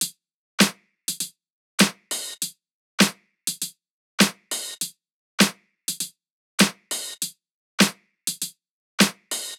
Unison Funk - 5 - 100bpm - Tops.wav